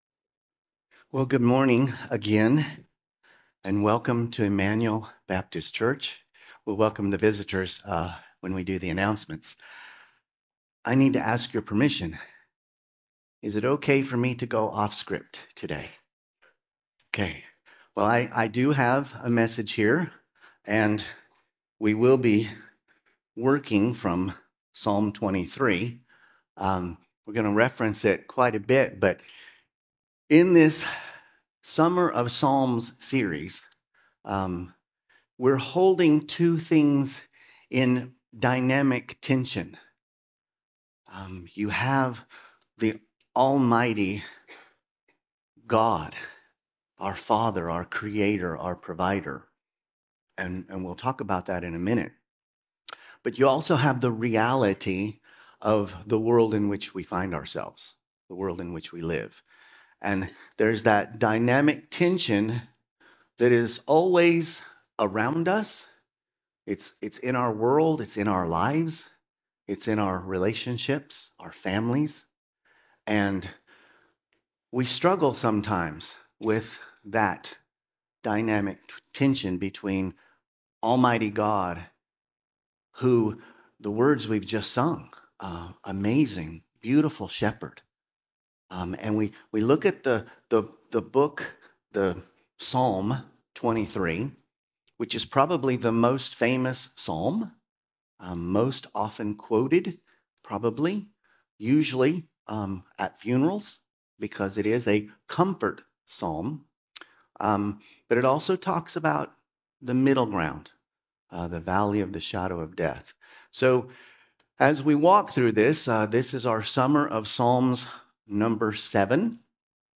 A message from the series "Sunday Service."
From Series: "Sunday Service"